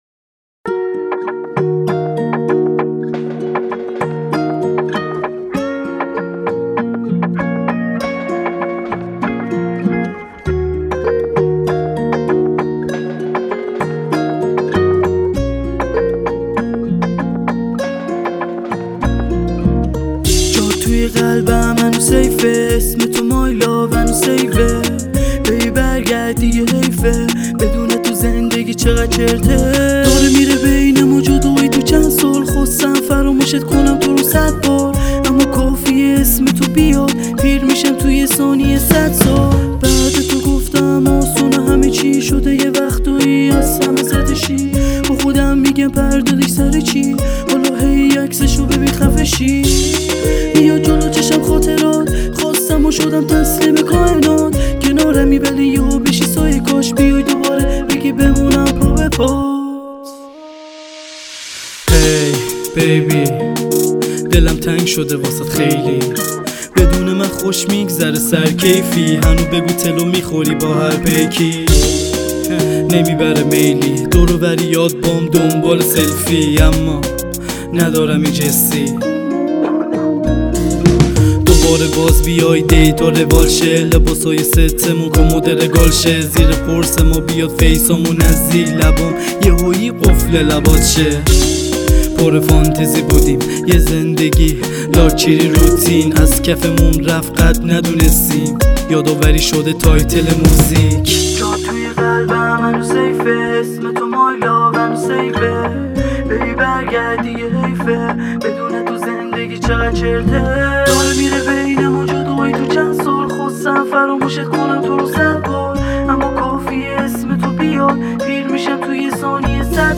Persian Music